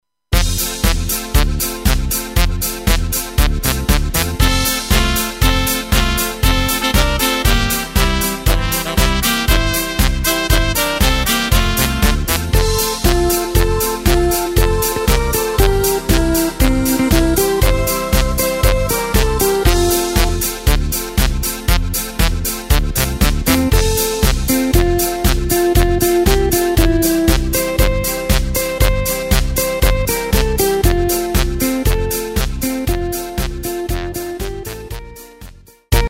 Takt:          2/4
Tempo:         118.00
Tonart:            F
Polka aus dem Jahr 1985!